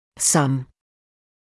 [sʌm][сам]некоторый; какой-нибудь; немного